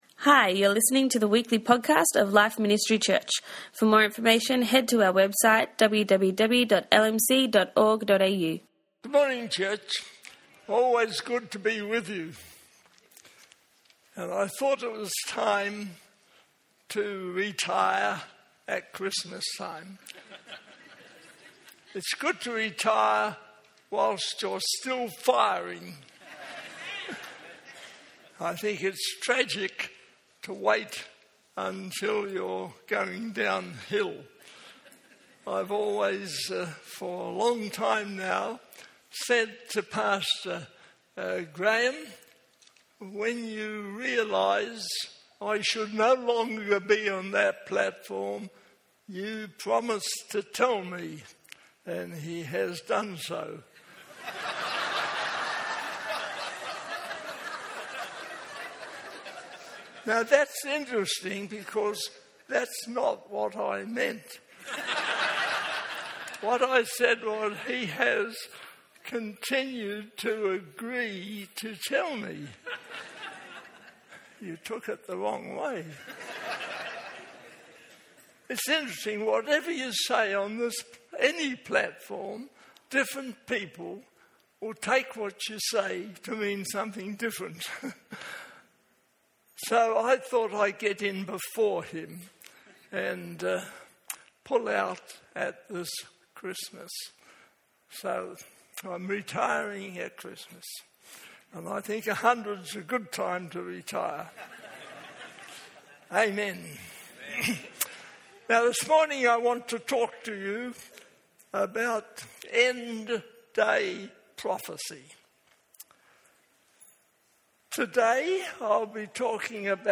message on end time prophecy. In it, he gave a great description of where we are at this point in time regarding the return of Jesus.